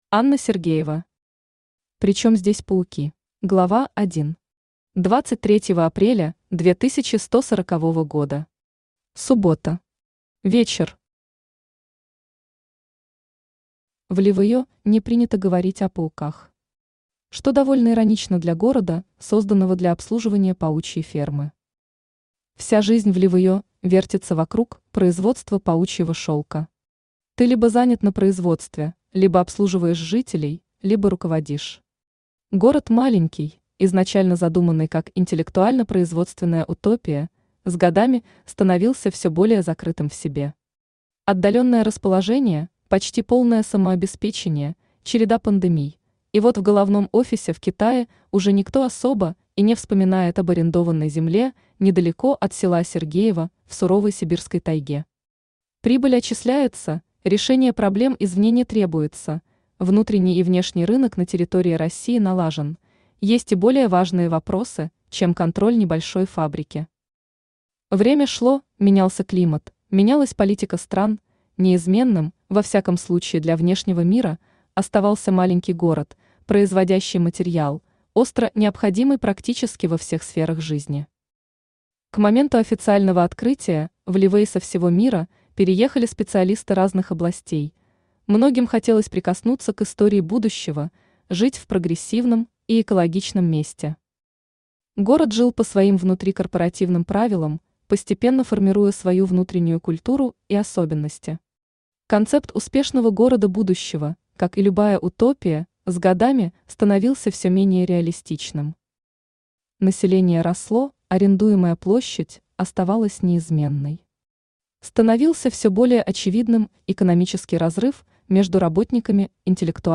Аудиокнига При чем здесь пауки | Библиотека аудиокниг
Aудиокнига При чем здесь пауки Автор Анна Игоревна Сергеева Читает аудиокнигу Авточтец ЛитРес.